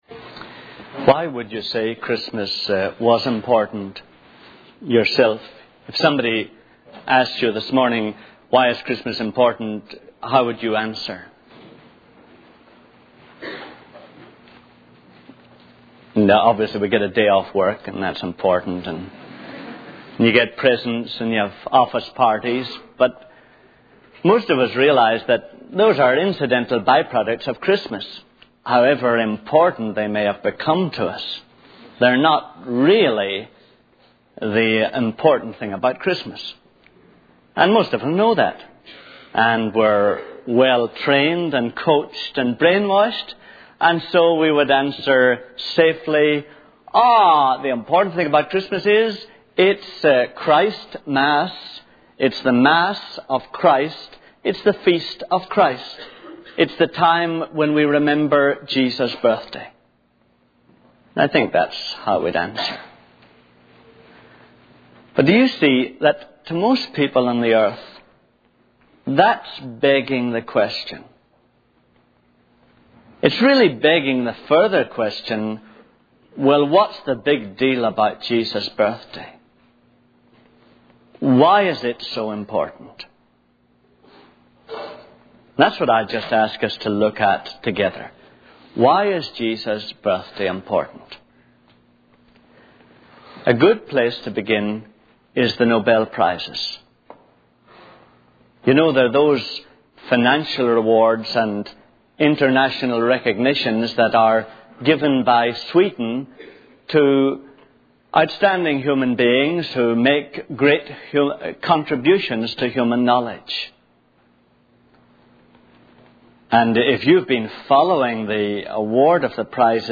In this sermon, the speaker emphasizes the importance of living a Christlike life rather than just talking about Christianity. He highlights the need for people to see God incarnate in the lives of believers, as this is what truly convinces others of the truth of the Gospel.